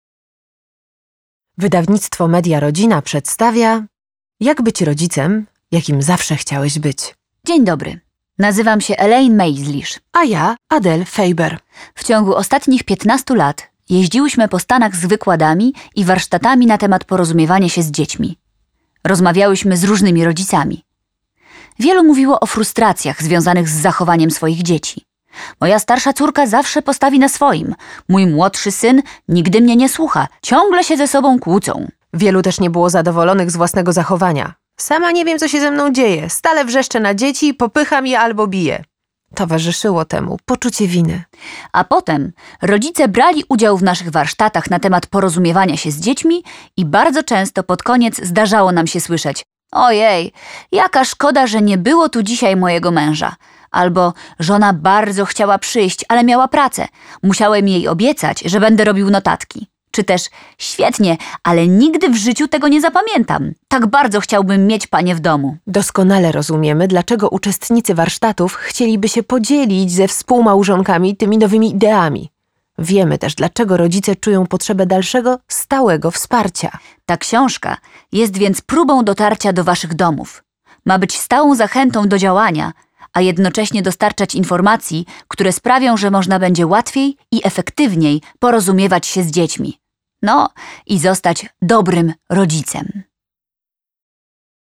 Jak być rodzicem, jakim zawsze chciałeś być - Adele Faber, Elaine Mazlish - audiobook